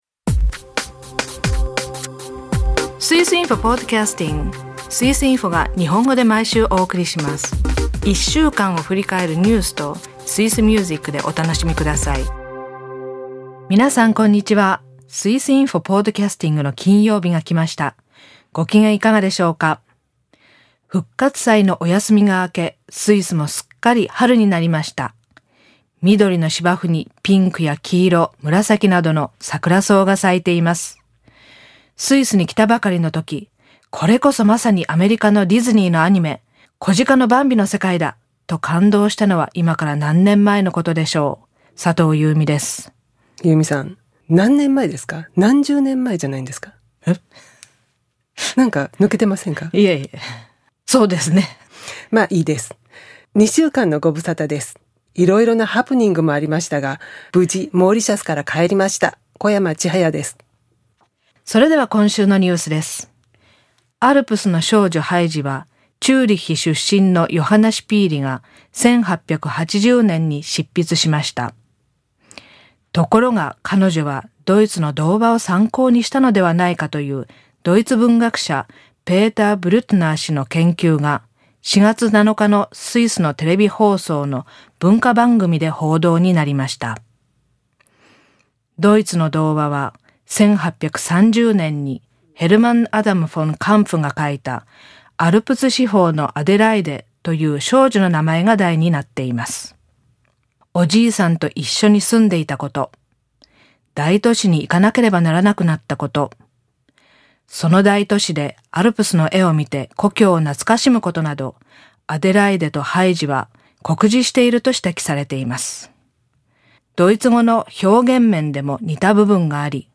朗読では、いよいよ傭兵になるきっかけが語られます。